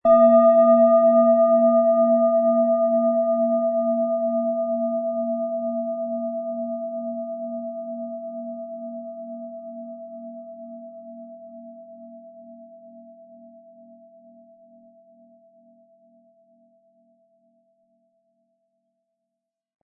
Planetenschale® Heilsame Energie erhalten & Ausgeglichen fühlen mit Hopi-Herzton & Mond, Ø 15,2 cm, 320-400 Gramm inkl. Klöppel
• Mittlerer Ton: Mond
PlanetentonHopi Herzton